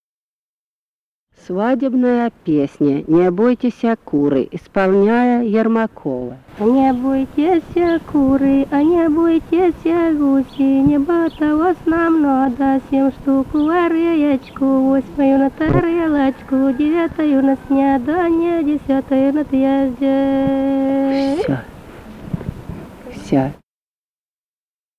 Музыкальный фольклор Климовского района 042. «А не бойтеся, куры» (свадебная).
Записали участники экспедиции